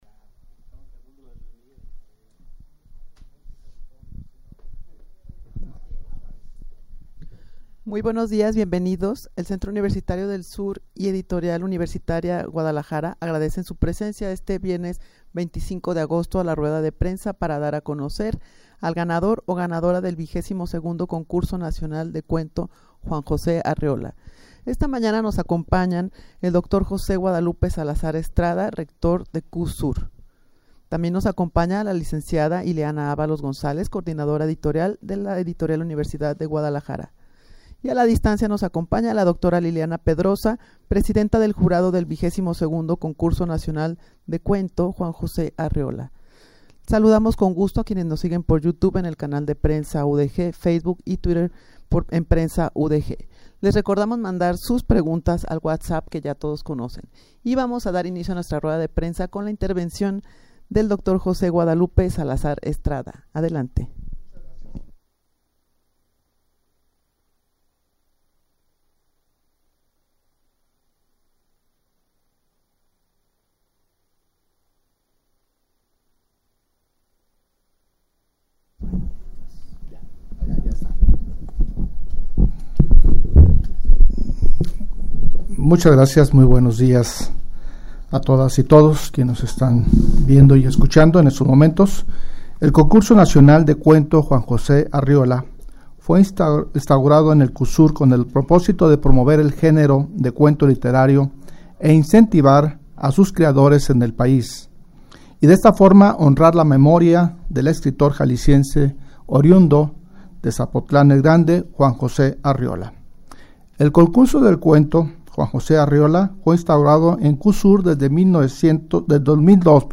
Audio de la rueda de Prensa
rueda-de-prensa-para-dar-a-conocer-al-ganador-o-ganadora-del-xxii-concurso-nacional-de-cuento-juan-jose-arreola.mp3